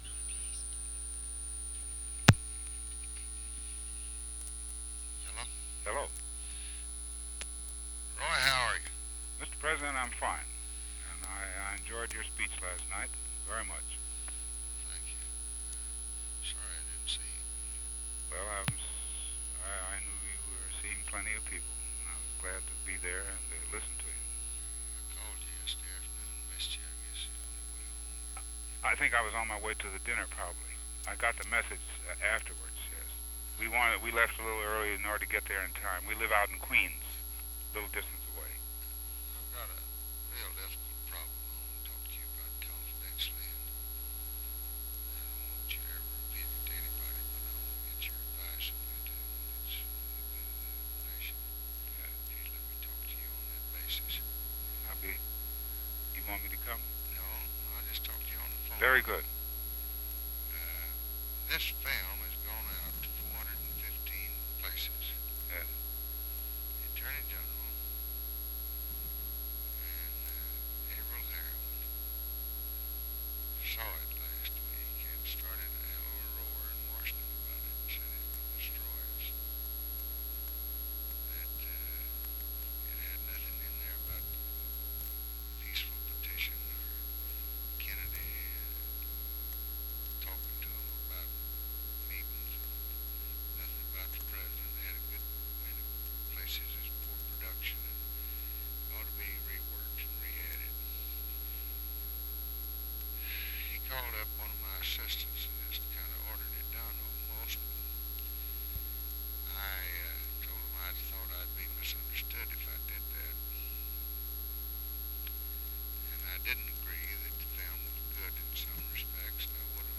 Conversation with ROY WILKINS, February 6, 1964
Secret White House Tapes